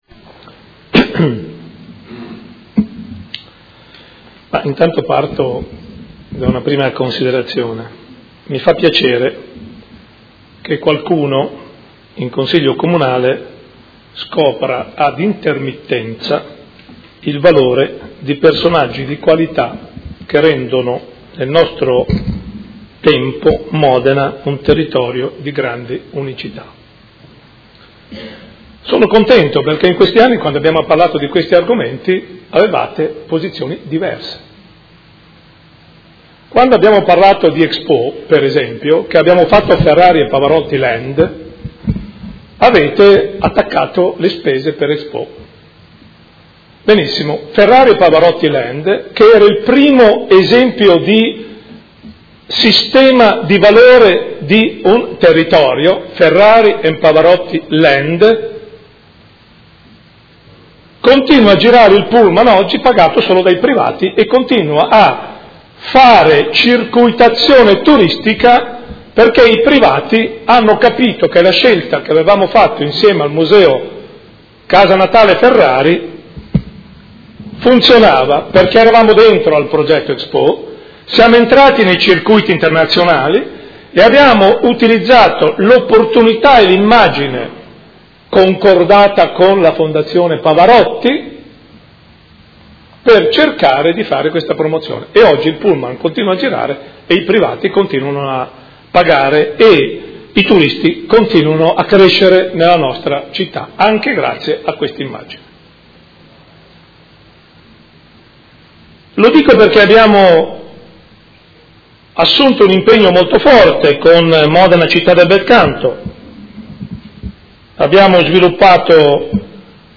Gian Carlo Muzzarelli — Sito Audio Consiglio Comunale
Seduta del 23/03/2017 Dibattito. Ordine del Giorno presentato dal Consigliere Galli (F.I.) avente per oggetto: Concerto/Tributo a Pavarotti a Verona nel decennale dalla scomparsa; a Modena possiamo piangerci addosso lamentandoci dello “scippo” subito o capire che il Concerto dell’Arena può essere un’opportunità.